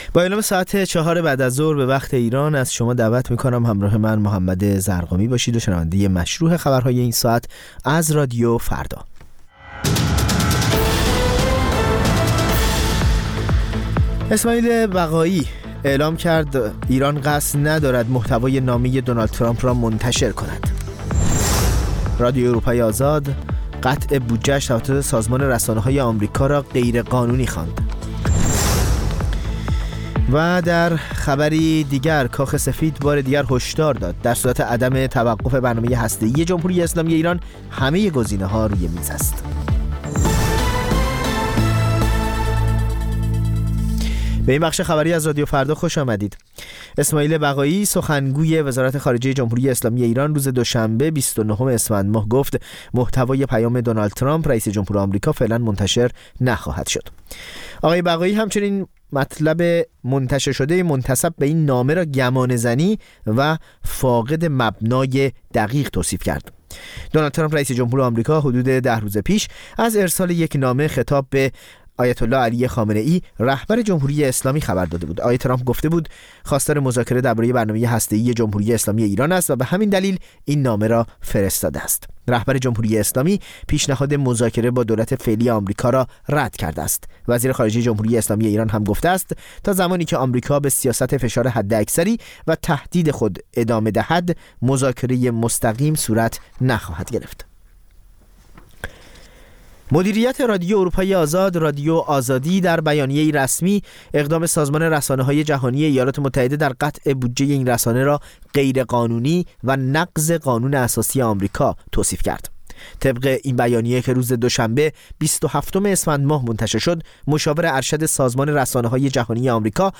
سرخط خبرها ۱۶:۰۰